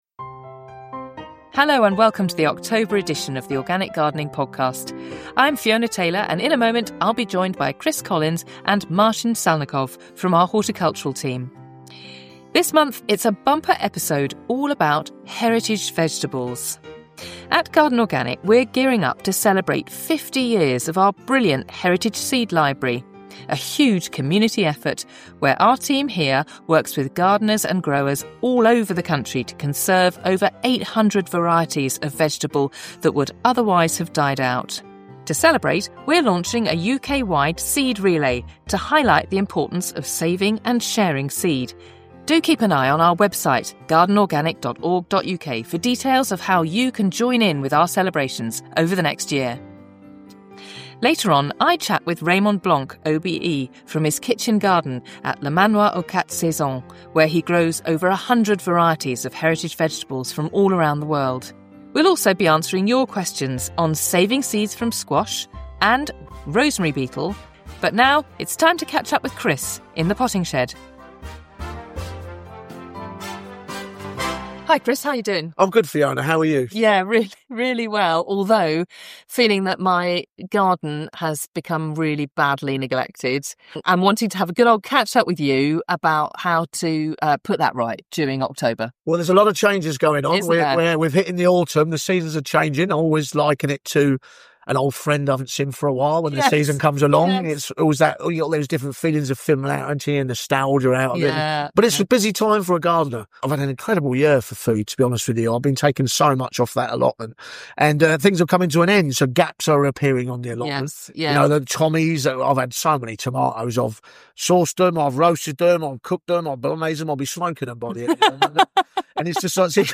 In this month’s episode, we're joined by Raymond Blanc OBE from his kitchen garden at Le Manoir aux Quat Saisons. We toured the garden, which is home to over 100 heritage vegetable varieties. Raymond talks about his own gardening heritage, and how growing with his mother and father in France influenced the direction he's taken with Le Manoir's kitchen garden.